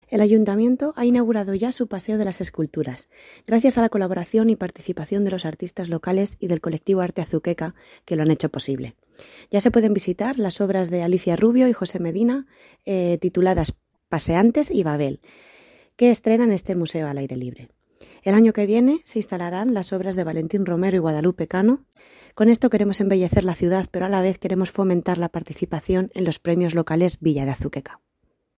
Declaraciones de la concejala Susana Santiago